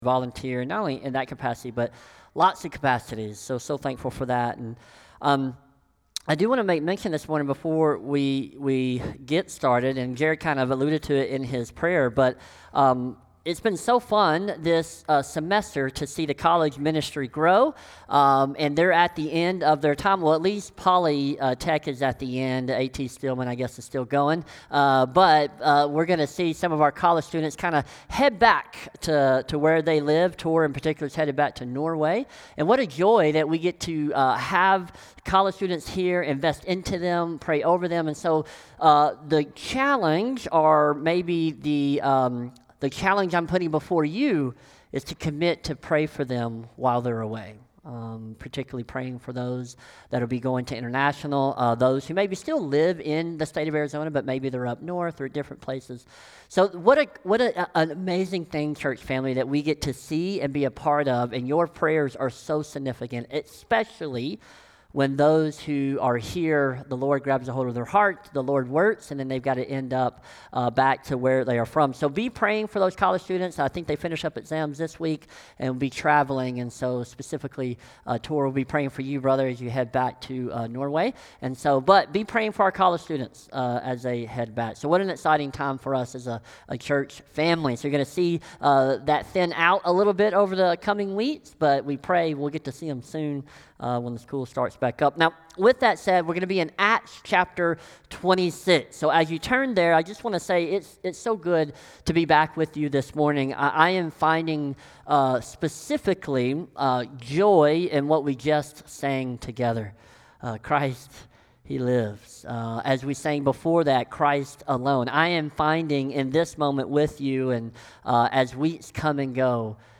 SERMON | Acts 26 | Stop Kicking | May 4th, 2025 | Light in the Desert Church